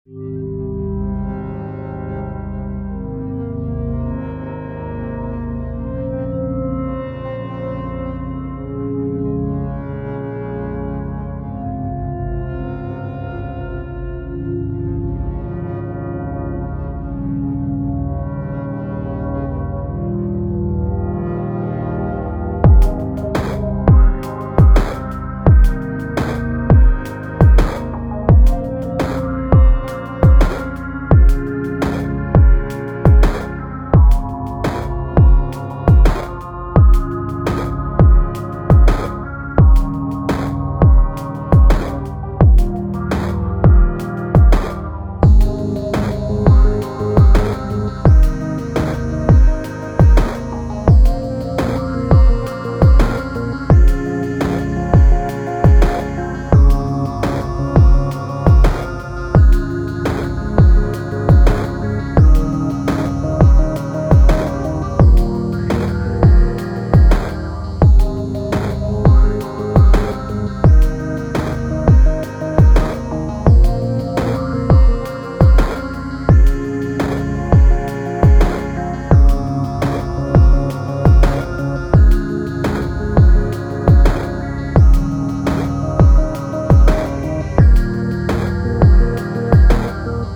Fucked up idea, should not probably try to use harmonic pad as bass. Noted and hi-passed. Digitone,System-1m, Neutron